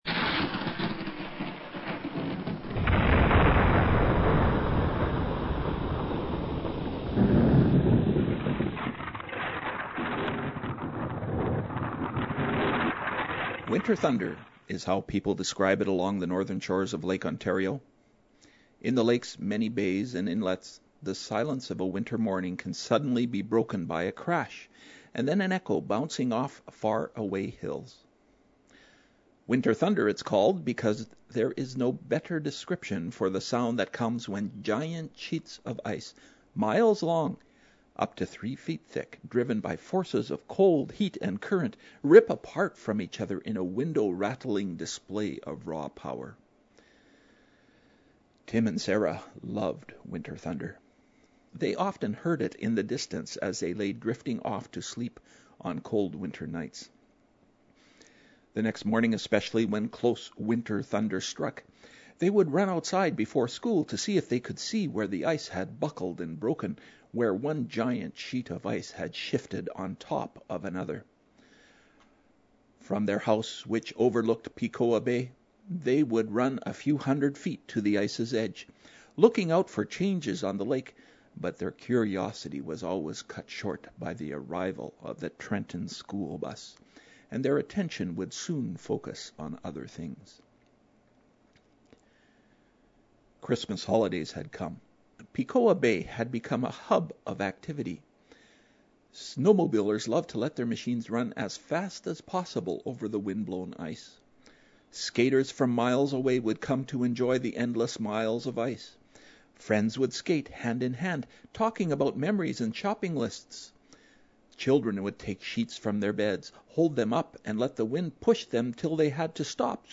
A Christmas Eve Story